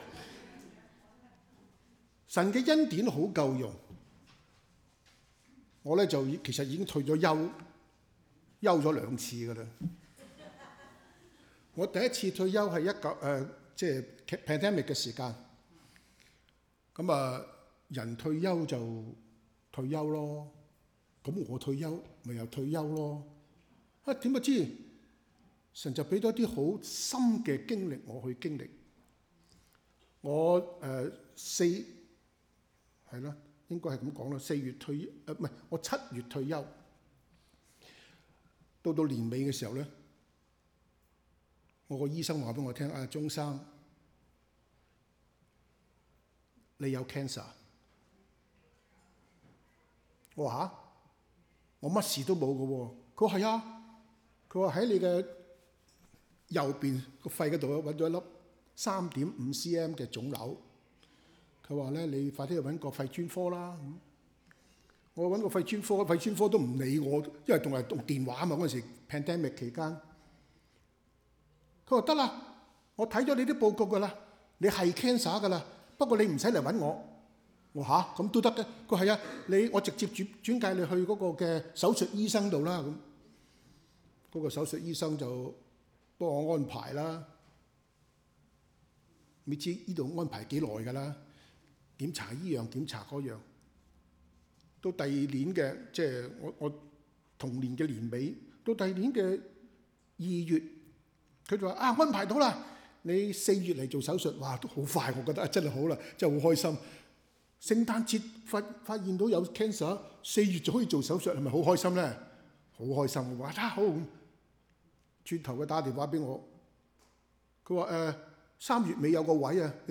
Sermons | New Covenant Alliance Church (NCAC) 基約宣道會 - Part 9